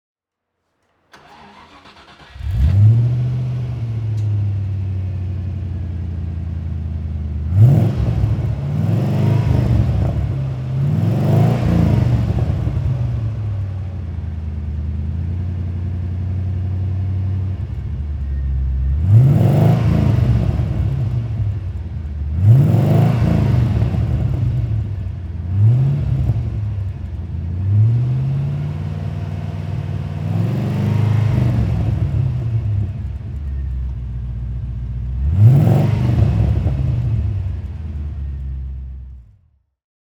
MG RV8 (1995) - Starten und Leerlauf